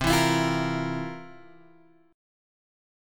C11 chord {8 7 x 9 6 6} chord